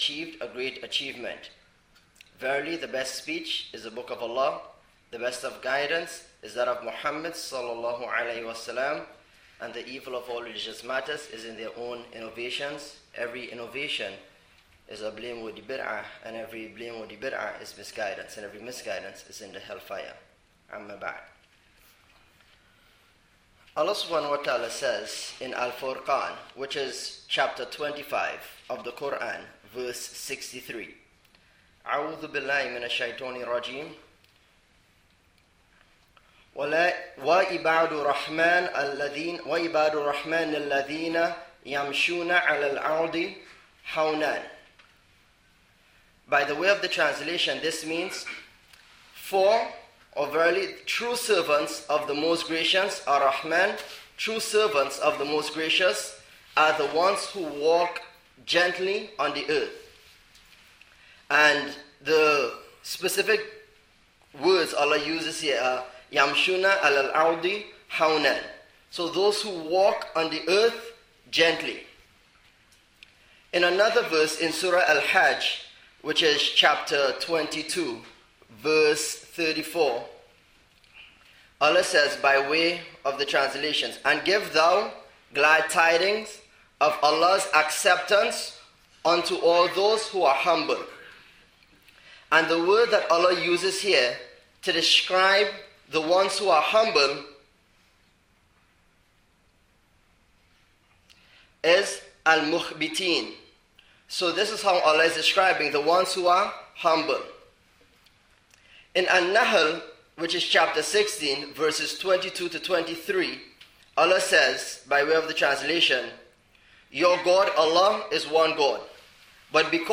Jumu'ah Khutbah: Pride vs Humility: The Battle for the Soul of the Believer (Masjid Quba | 9/23/11)